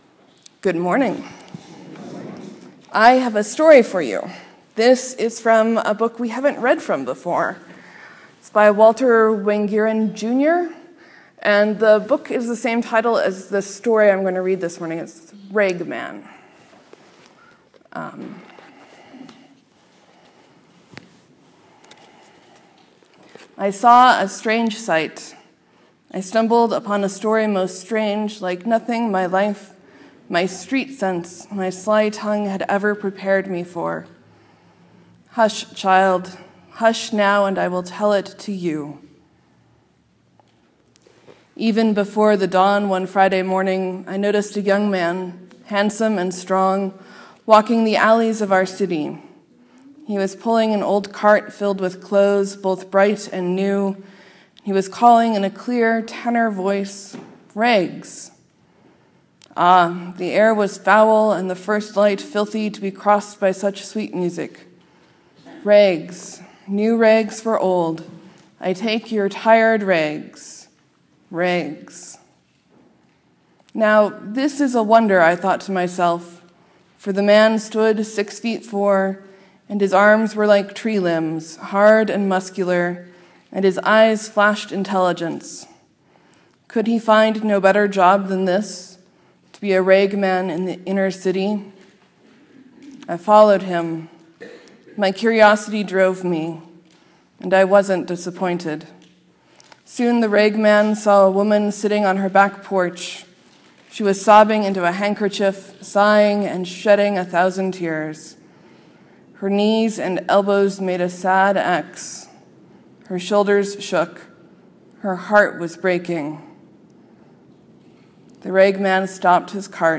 Morsels & Stories: I read the “Ragman” from the book Ragman: and other cries of faith by Walter Wangerin Jr.
Sermon: Jesus talks to Mary (and Martha before her) and then gives them a job.